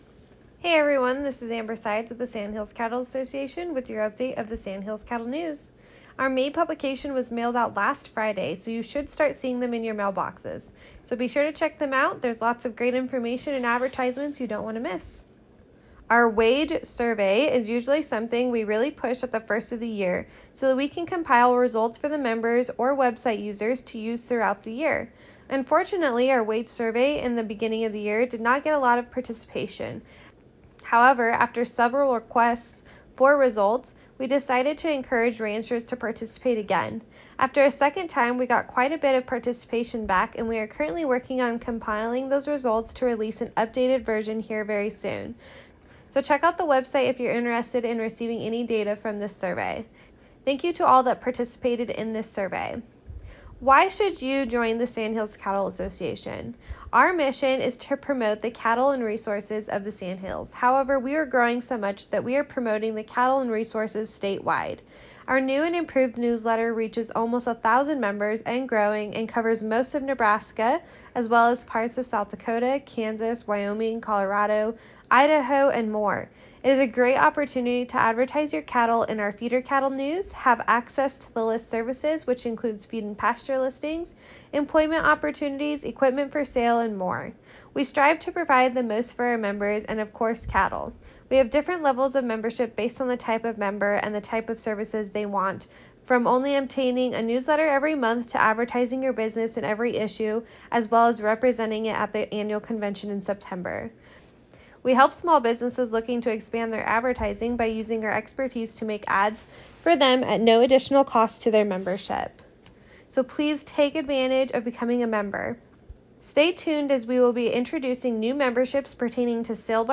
May 20, 2021 SCA Radio Spot